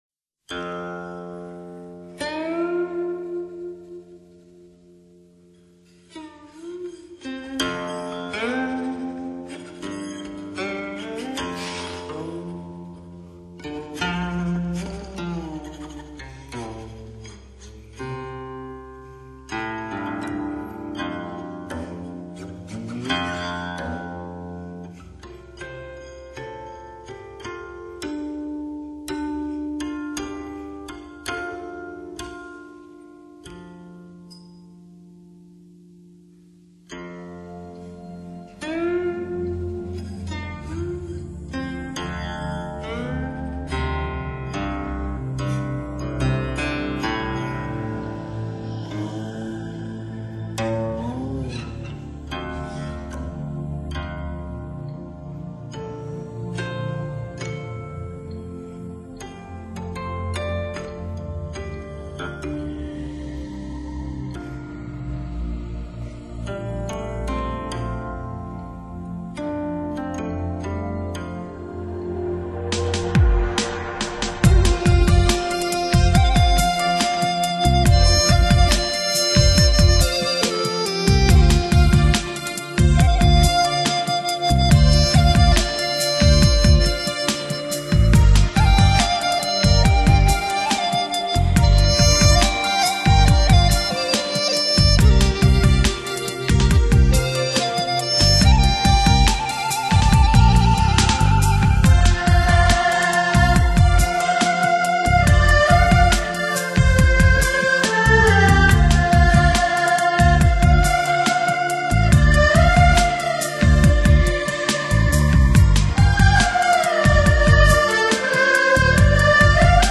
以电子音乐和传统中乐巧妙融合，表现梦的迷幻、神秘、幻变不断、让人意想不到。